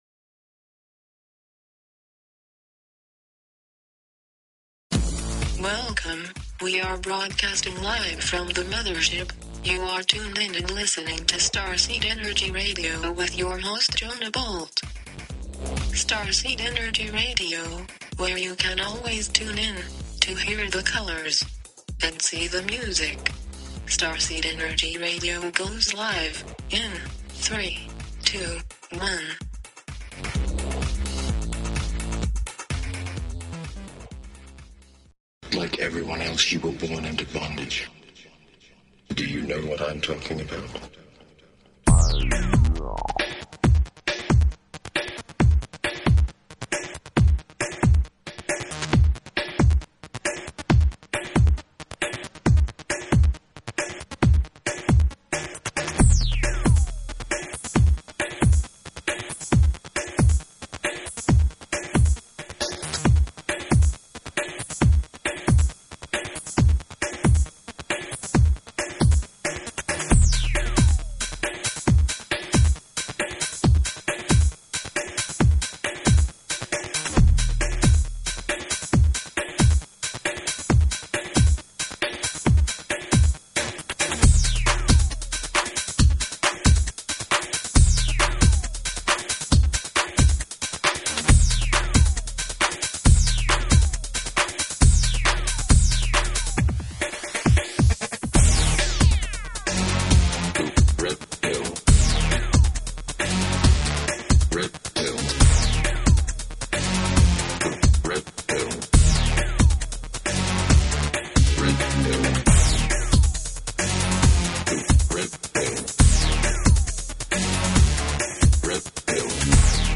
Talk Show Episode, Audio Podcast, Starseed_Energy_Radio and Courtesy of BBS Radio on , show guests , about , categorized as